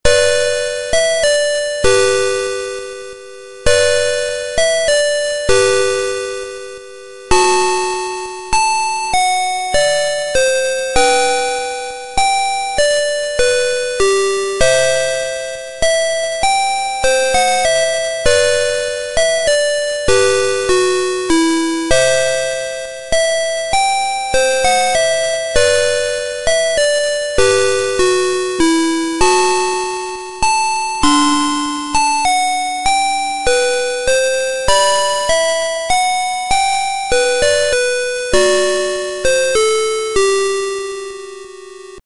12 Children's Favorites Dual-Tong Melody
• Dual tone melody